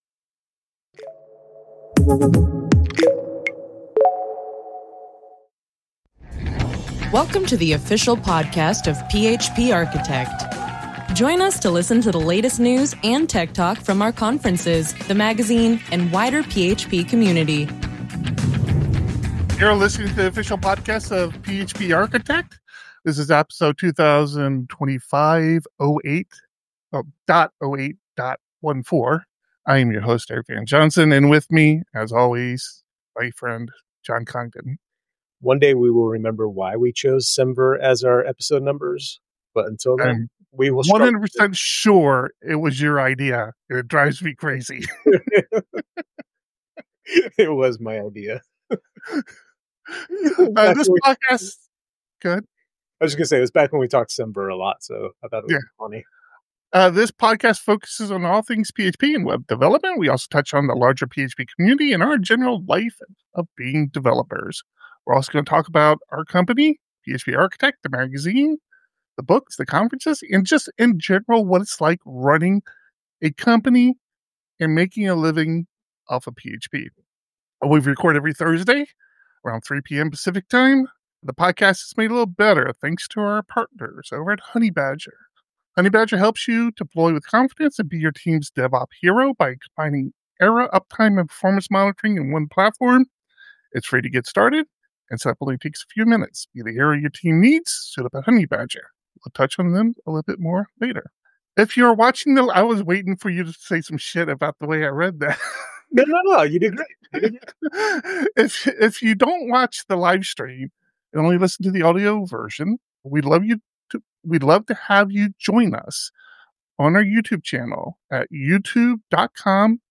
The PHP Podcast streams the recording of this podcast live, typically every Thursday at 3 PM PT.